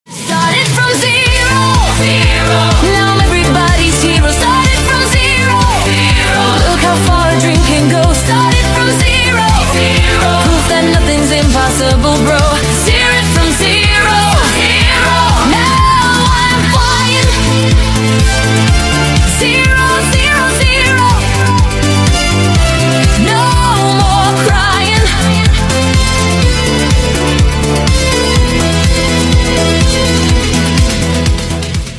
танцевальные , евродэнс , зажигательные